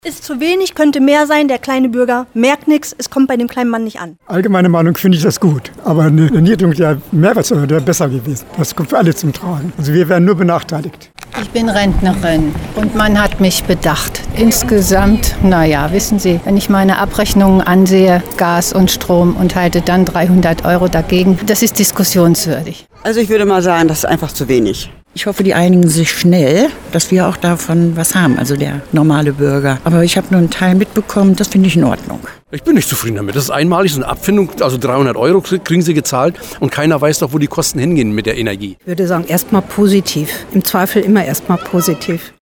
Aktuelle Lokalbeiträge Hameln: UMFRAGE ENTLASTUNGSPAKET Play Episode Pause Episode Mute/Unmute Episode Rewind 10 Seconds 1x Fast Forward 30 seconds 00:00 / Download file | Play in new window Umfrage: Die Meinungen der Hamelner zu dem nächsten Entlastungspacket der Ampelkoalition…
hameln-umfrage-entlastungspaket.mp3